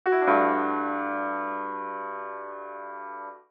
04_LoFi.wav